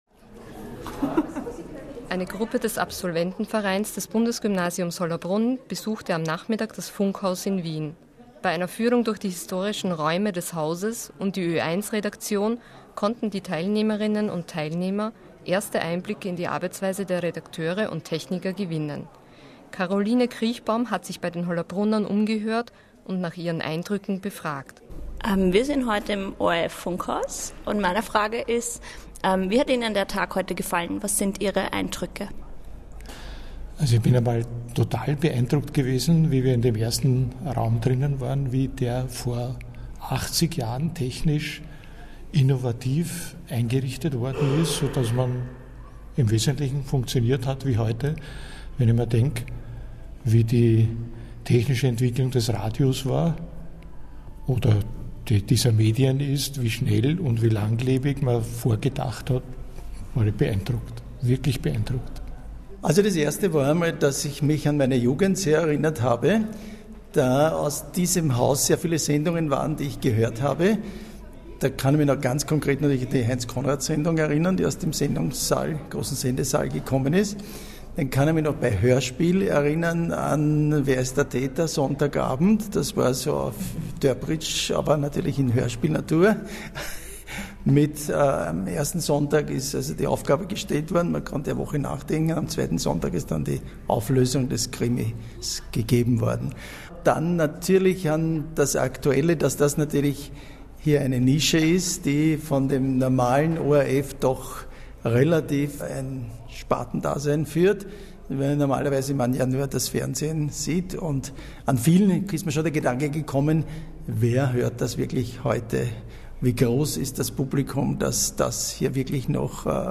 > Reportage zum "Nachhören"